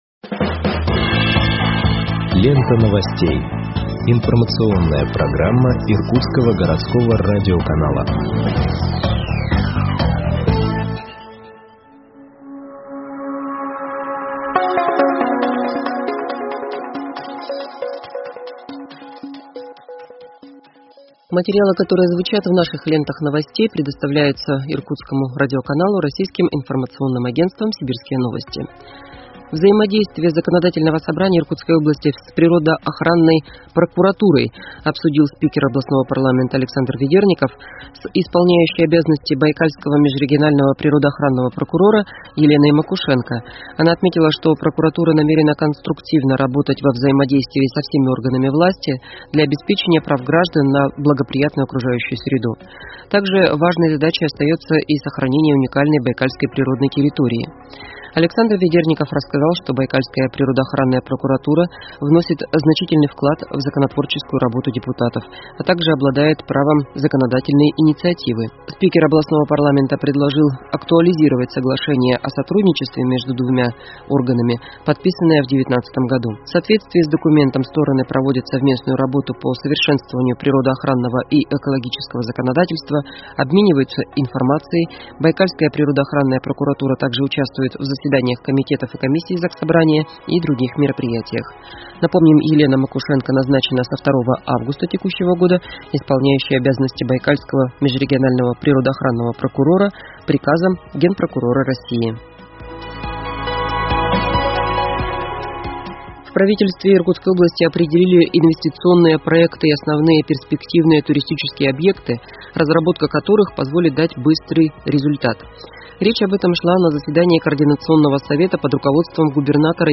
Выпуск новостей в подкастах газеты Иркутск от 12.08.2021 № 1